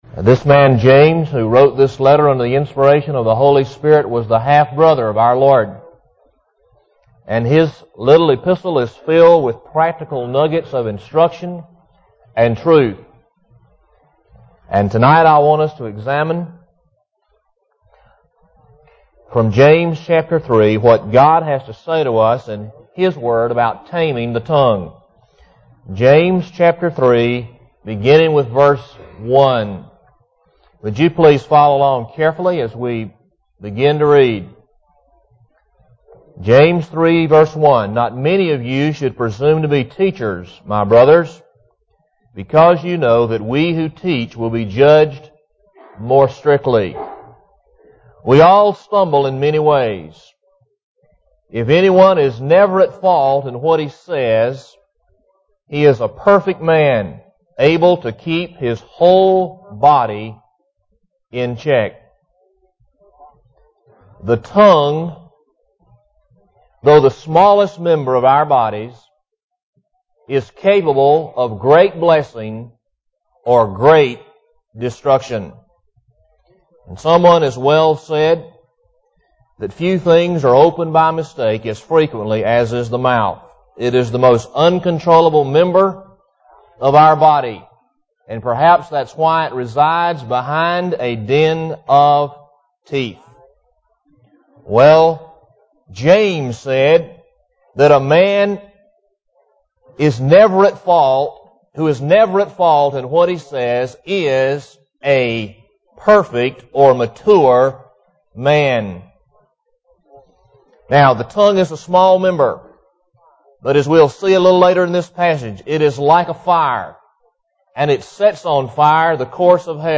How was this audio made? Taming the Tongue (Tape Failure, Use FK022)